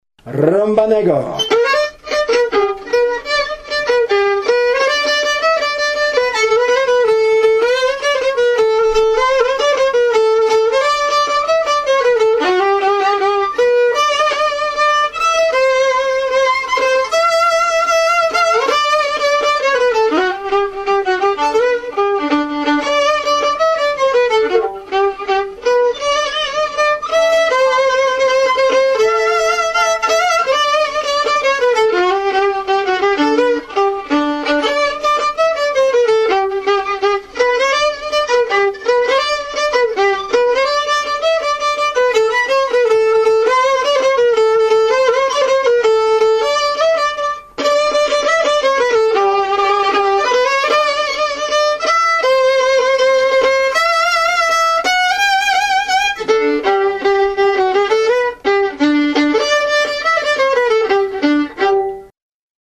Gra przede wszystkim na skrzypcach, ale również na „harmonii” (akordeonie) i organach.